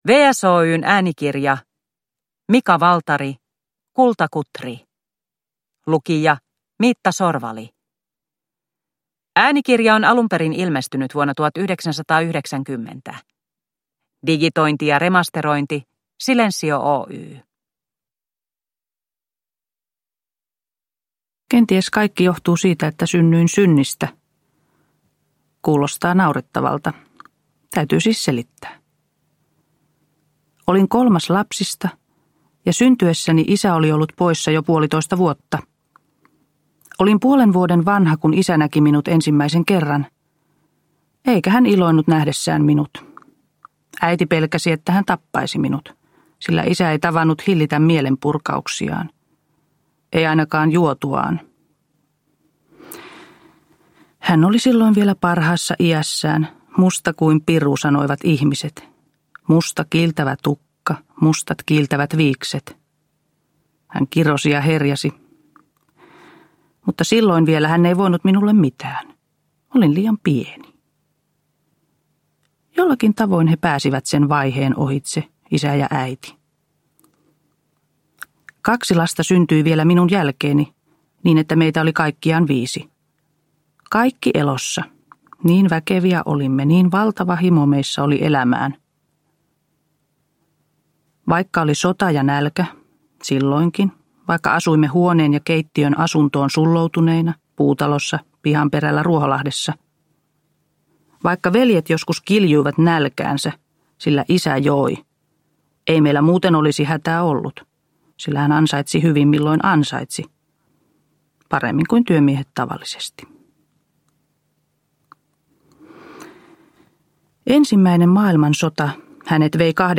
Kultakutri – Ljudbok – Laddas ner